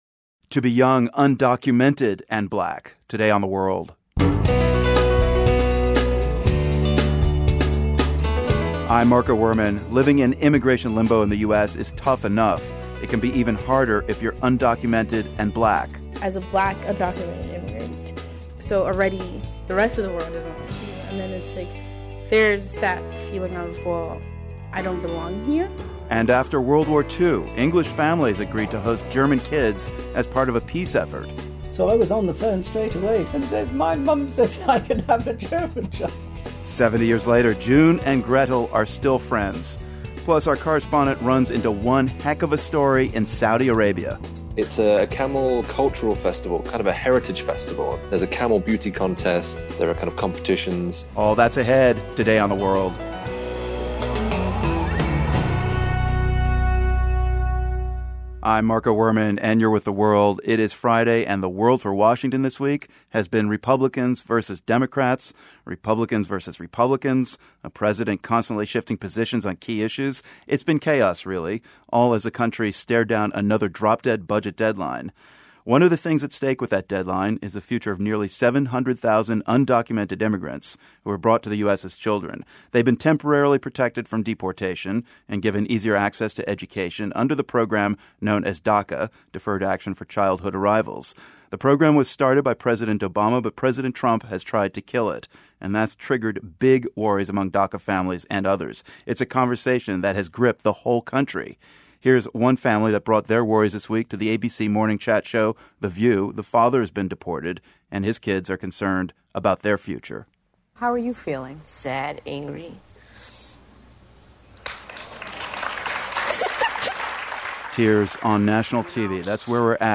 As part of our DACA Diaries series, today a young woman from Zambia talks about her struggles of not only being undocumented, but also being black. Plus, two takes on the Bitcoin craze. And a rare look inside the Kingdom of Saudi Arabia.